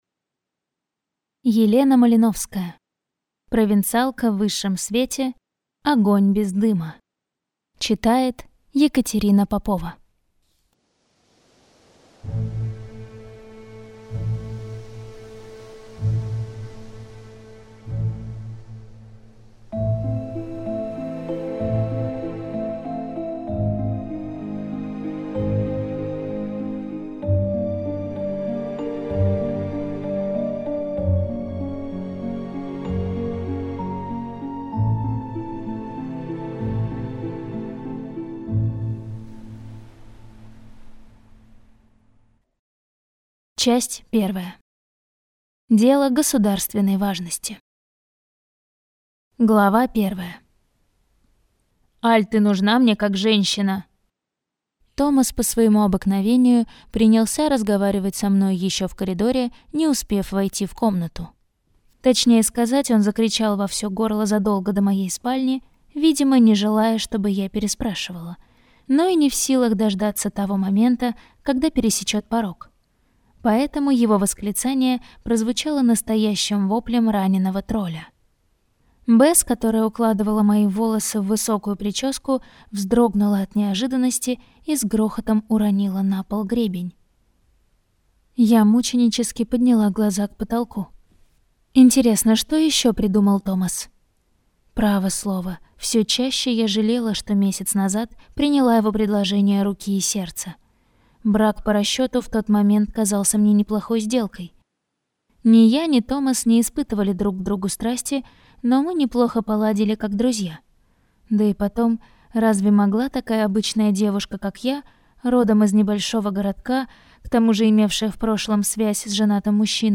Аудиокнига Провинциалка в высшем свете. Огонь без дыма - купить, скачать и слушать онлайн | КнигоПоиск